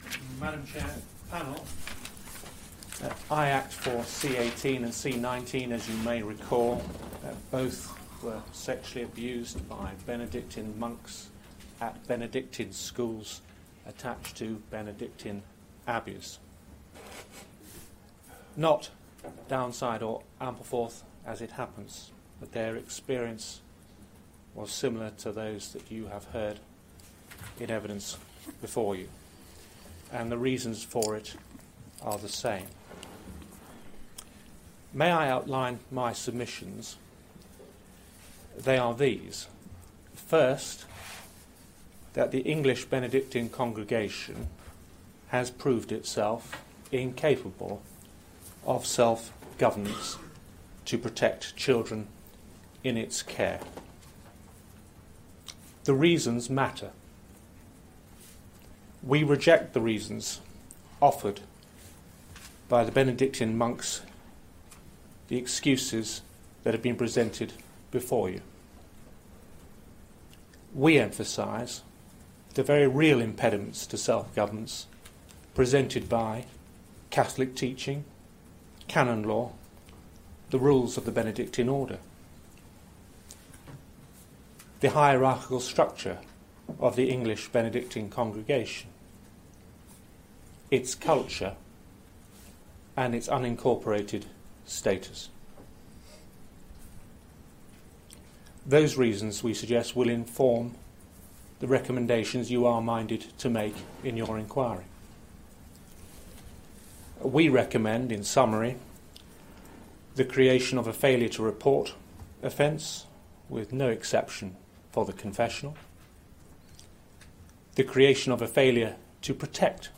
Closing statement